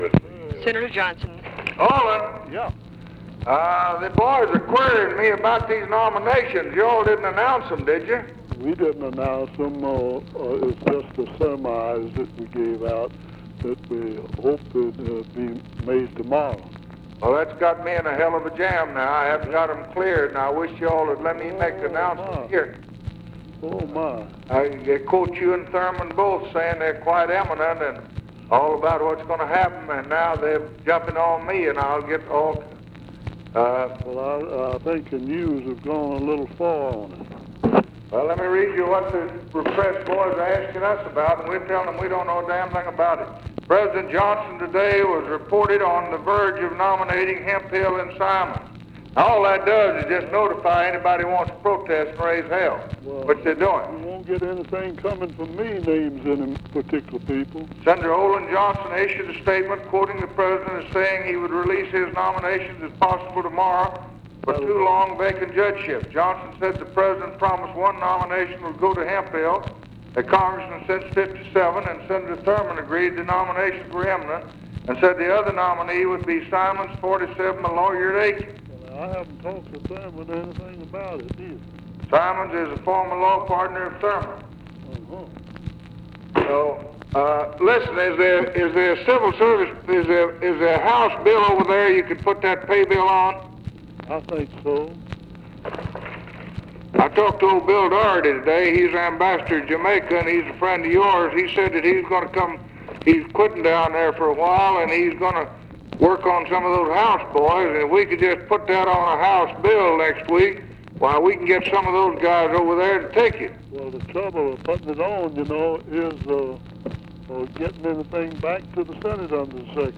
Conversation with OLIN JOHNSTON, April 14, 1964
Secret White House Tapes